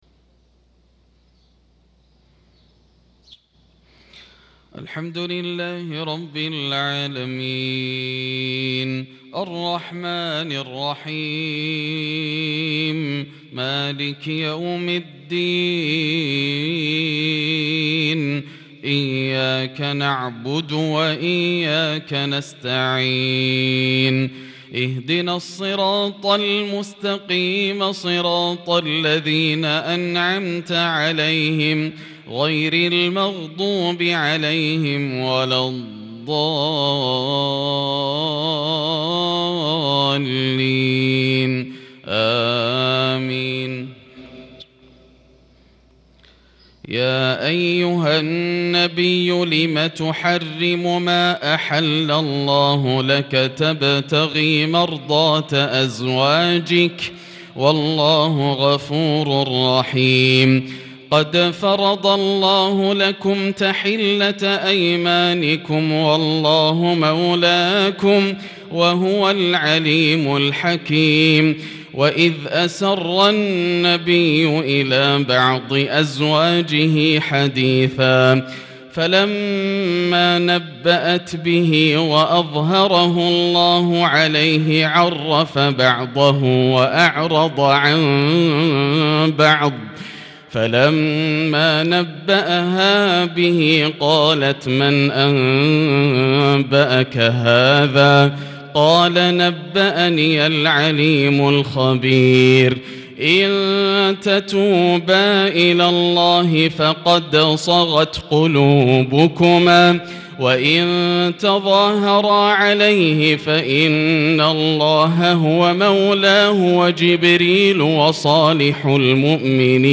صلاة الفجر للقارئ ياسر الدوسري 22 جمادي الأول 1443 هـ
تِلَاوَات الْحَرَمَيْن .